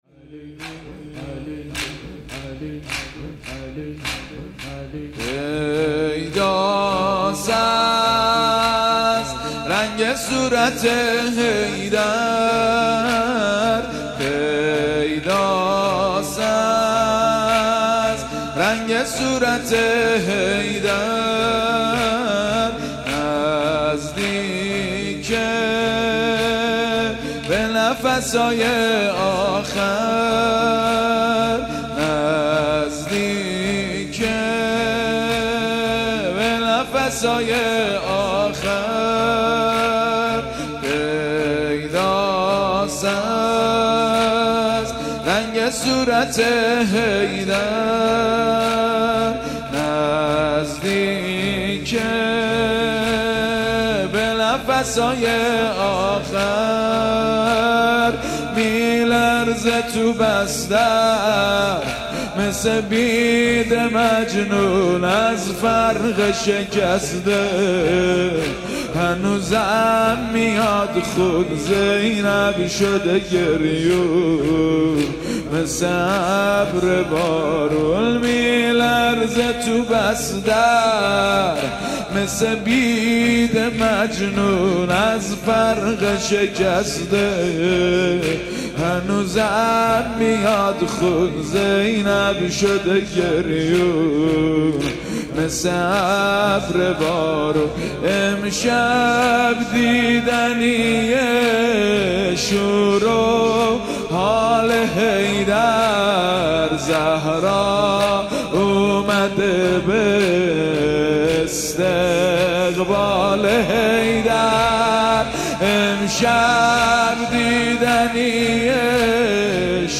مداحی
درمسجد کربلا برگزار گردید.